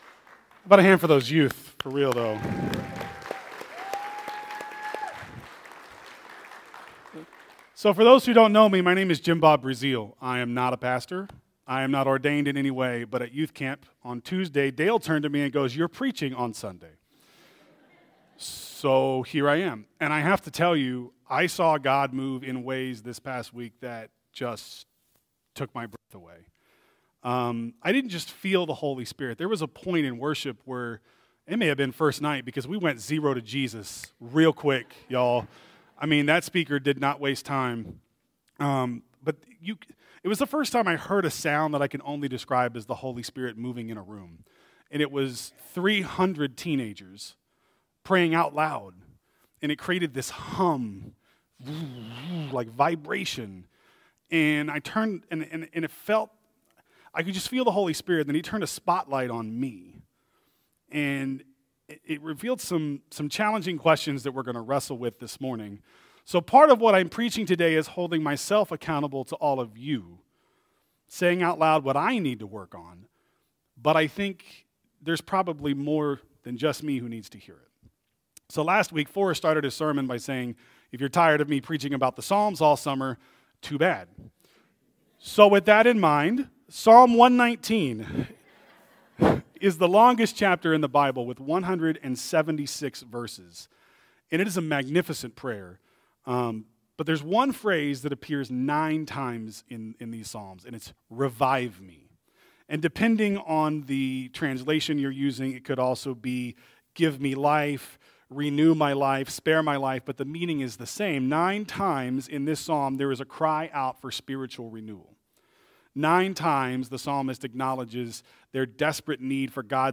Sermons | Asbury Methodist Church